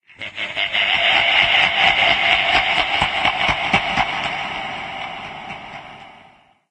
~controller_attack_2.ogg